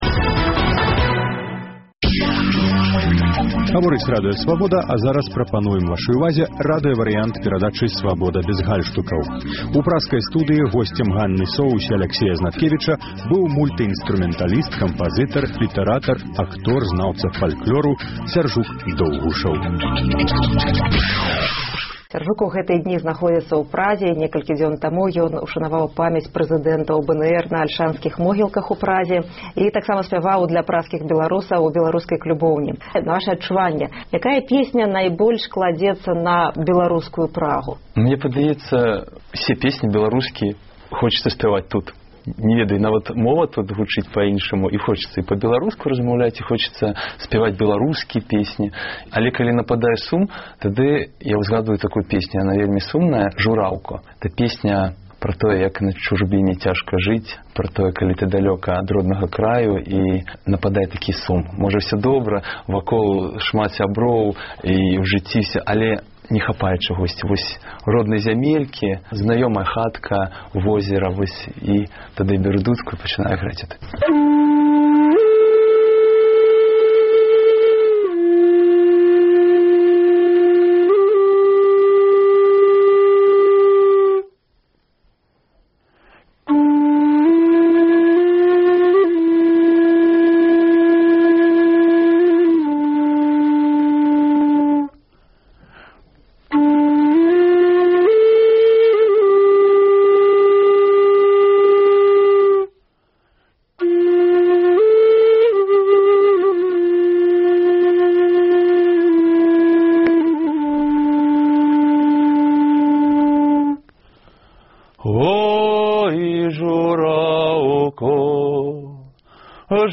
У праскай студыі "Свабоды бяз гальштукаў"